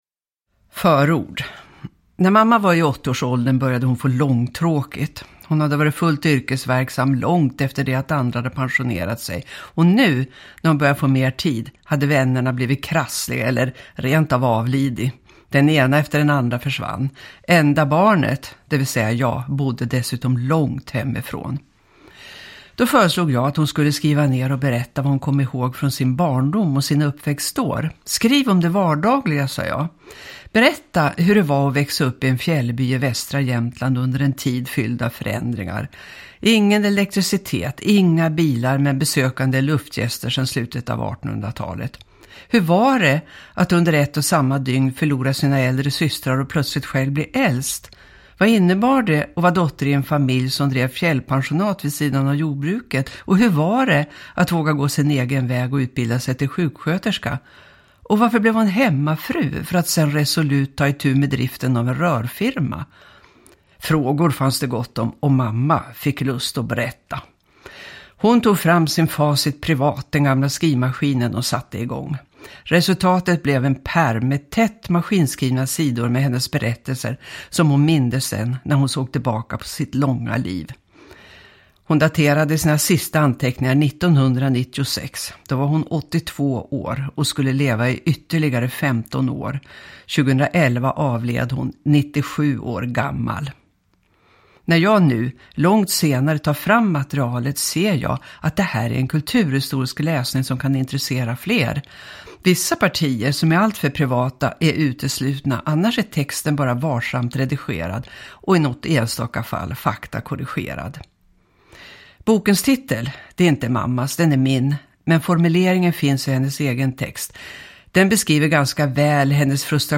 En bångstyrig dotter – Ljudbok – Laddas ner